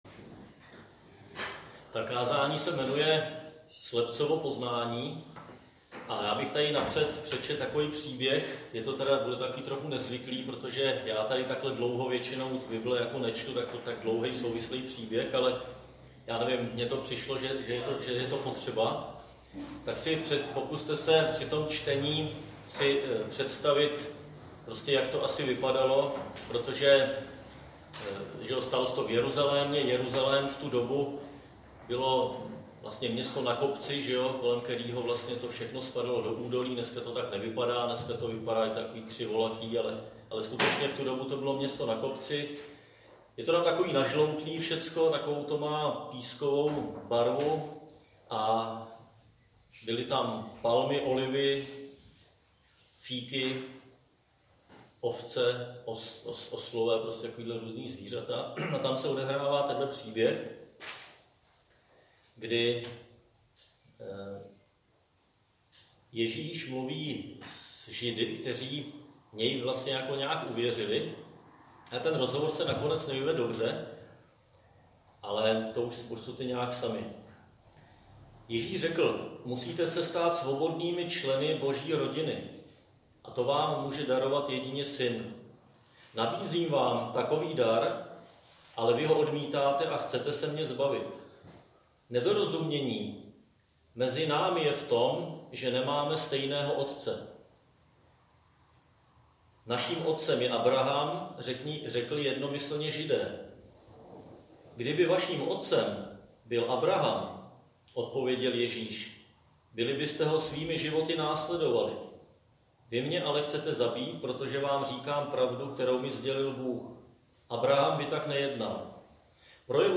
Křesťanské společenství Jičín - Kázání 23.6.2019